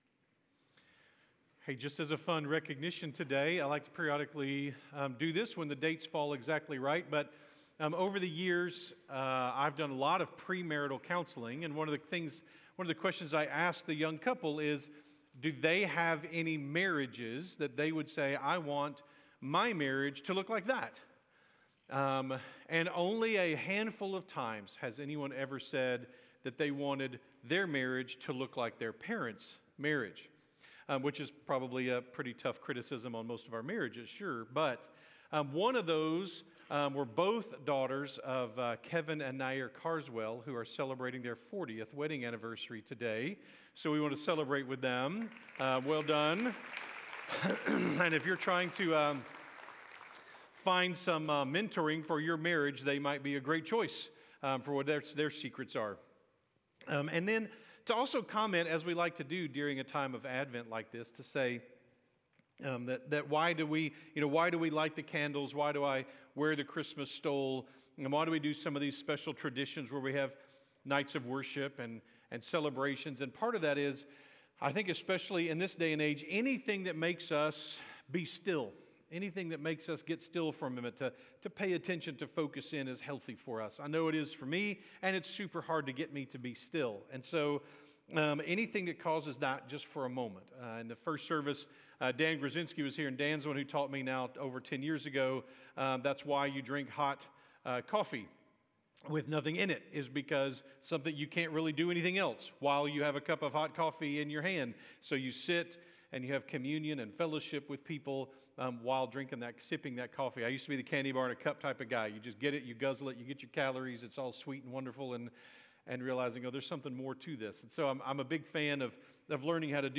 by South Spring Media | Dec 7, 2025 | 2025 Sermons, Advent 2025 | 0 comments
Dec-7-2025-Sunday-Morning.mp3